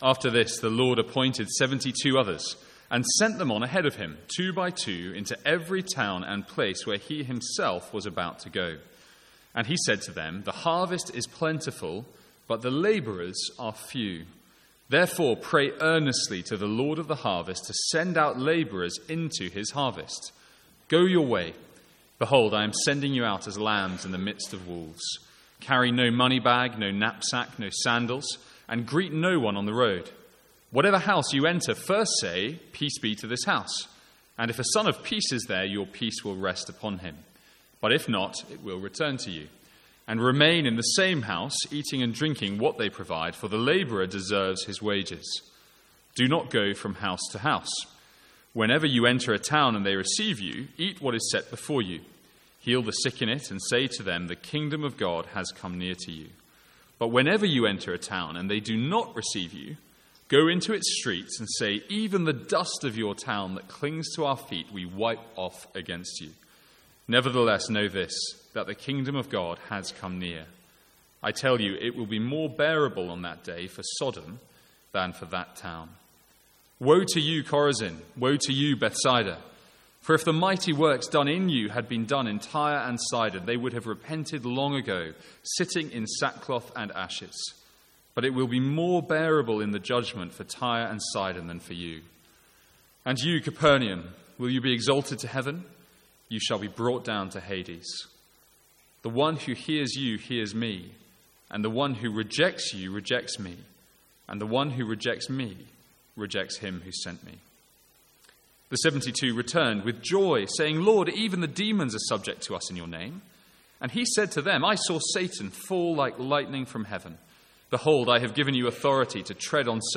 Sermons | St Andrews Free Church
From the Sunday evening series in Luke.